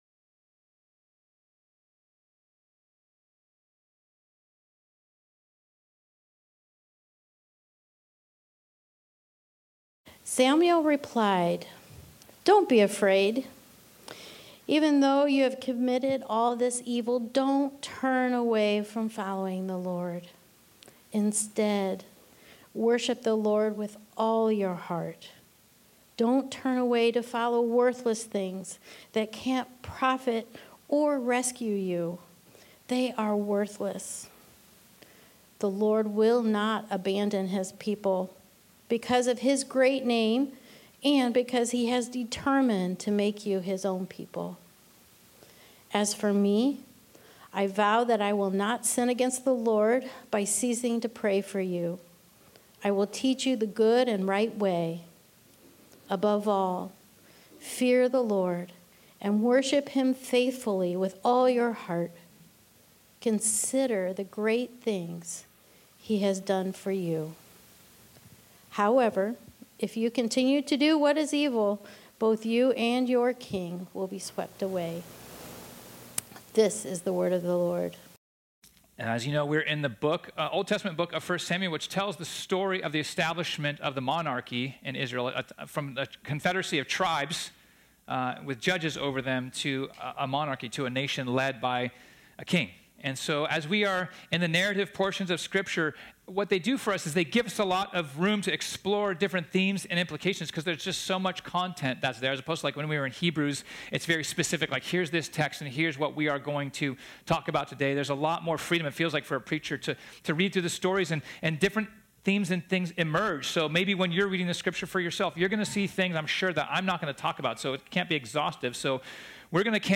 This sermon was originally preached on Sunday, May 7, 2023.